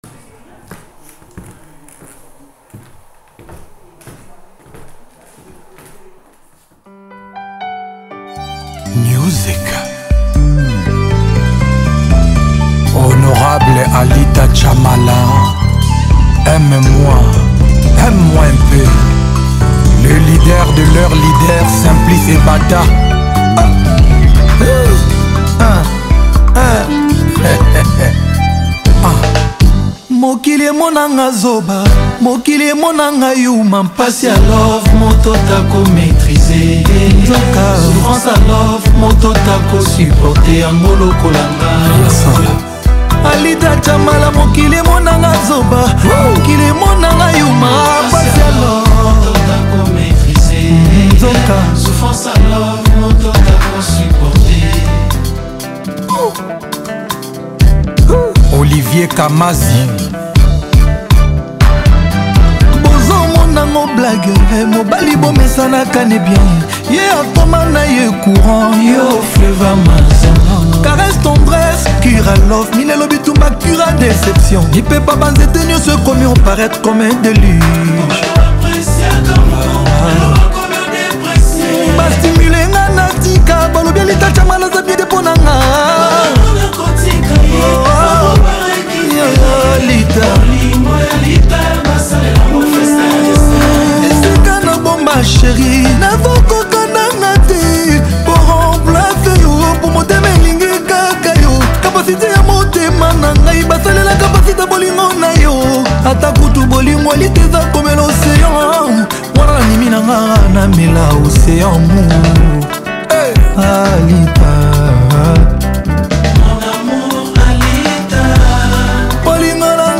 | Afro Congo